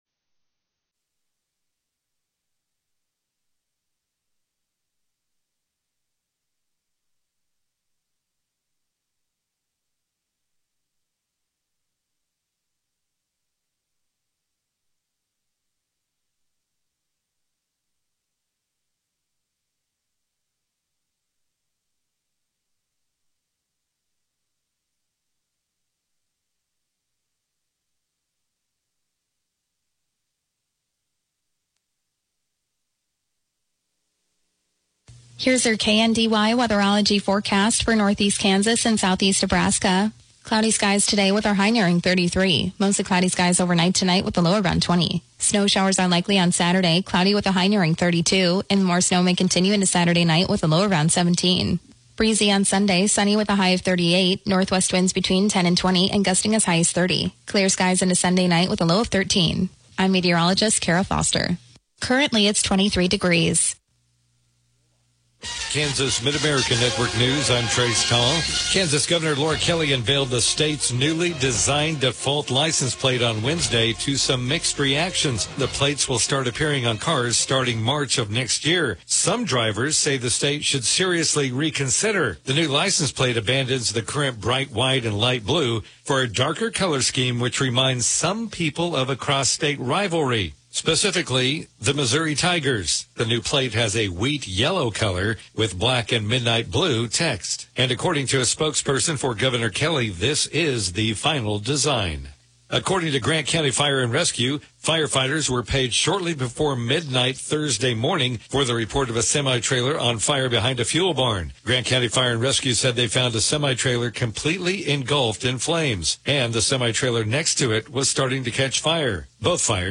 The KNDY Morning News podcast gives you local, regional, and state news as well as relevant information for your farm or home as well. Broadcasts are archived daily as originally broadcast on Classic Country 1570 AM, 94.1 FM KNDY.